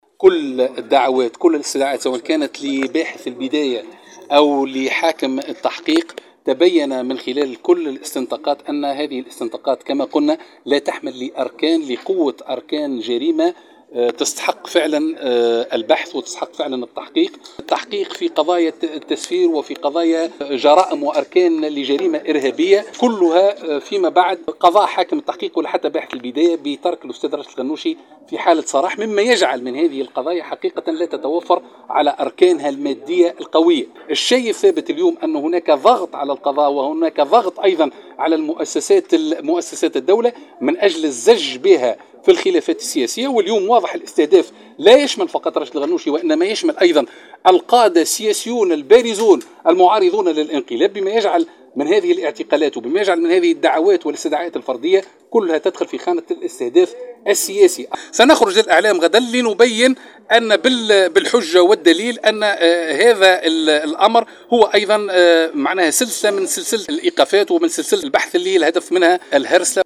اكد الناطق الرسمي لحركة النهضة عماد الخميري استدعاء رئيس الحركة راشد الغنوشي للتحقيق معه مجددا غدا الثلاثاء في خضم حملة امنية تستهدف العديد من القادة السياسيين والوجوه الاعلامية قامت بها السلطة التنفيذية مؤخرا وخلال ندوة صحفية لحركة النهضة اليوم بمقرها بالعاصمة وصف الخميري القضية المرفوعة ضد الغنوشي بالمفبركة وتدخل في مسار الهرسلة و الاعتقالات السياسية […]